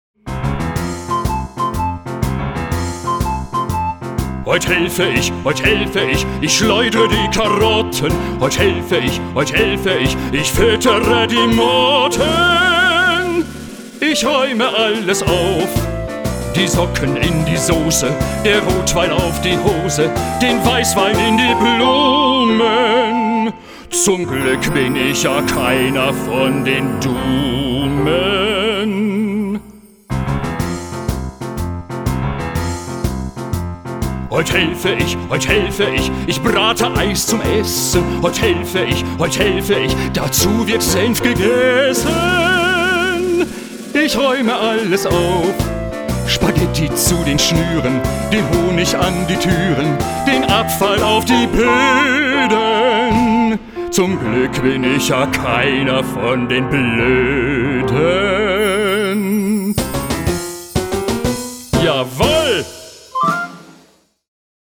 Eine CD mit allen Liedern und der Geschichte zum Anhören